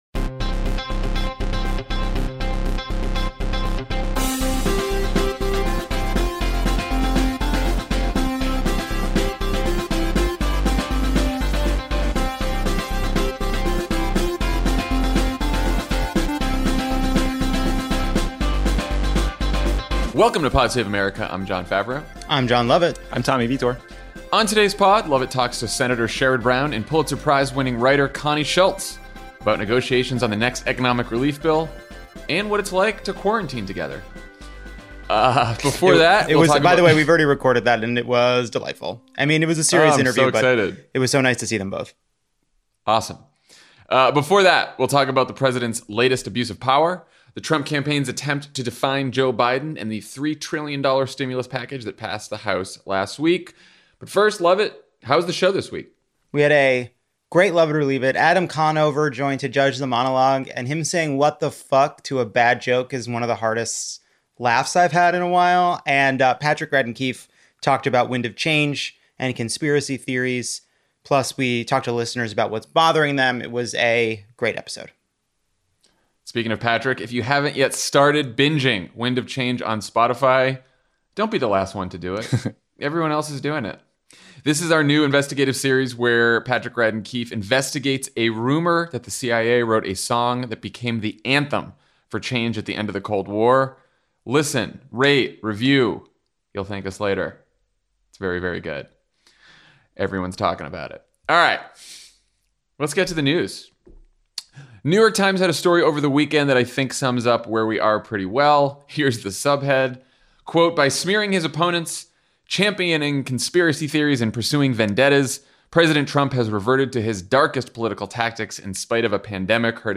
The President continues his purge of government officials who expose corruption, the Trump campaign uses a kitchen sink strategy to define Joe Biden, Barack Obama reminds us of what a normal president sounds like, and House Democrats pass an economic relief bill with some defections. Then Senator Sherrod Brown and Pulitzer Prize-winning journalist Connie Schultz talk to Jon L. about Senate negotiations over the next stimulus bill, and what it’s like to quarantine together.